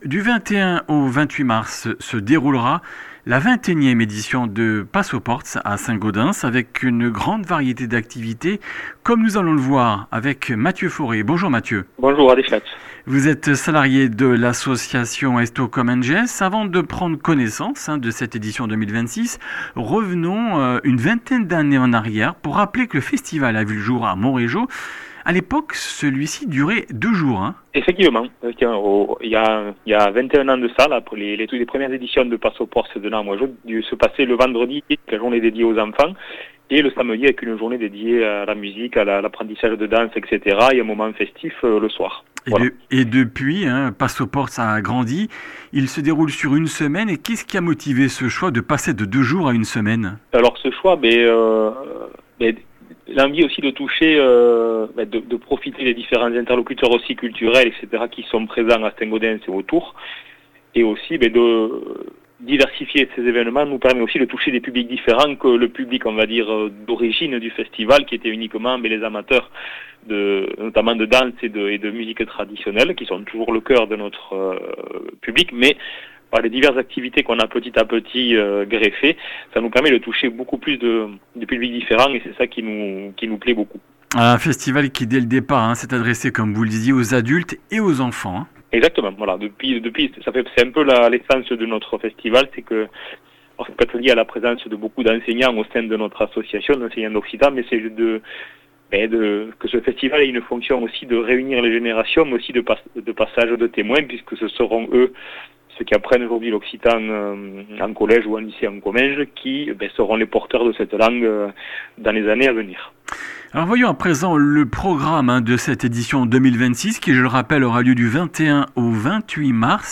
Comminges Interviews du 18 mars